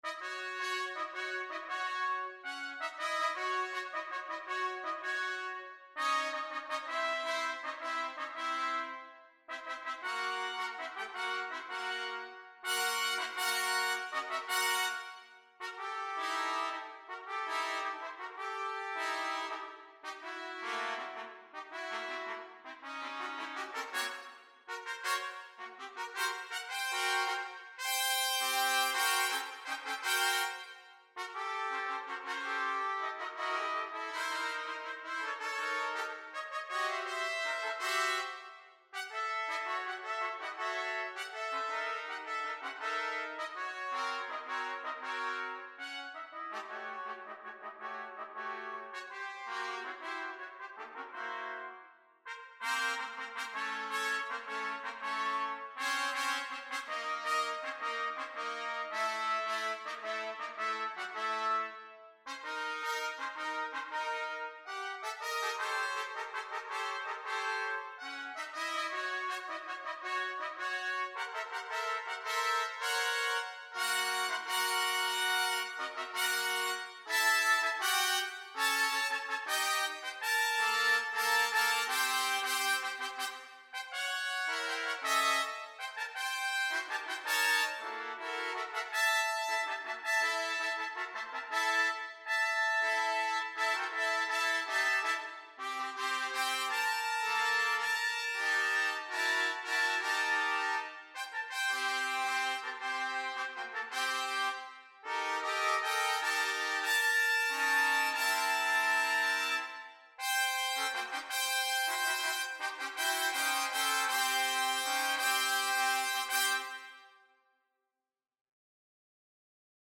Brass Ensembles
bullet  2025 Jump Start (4 trumpets) (2') [ACA]
(synthesized recording)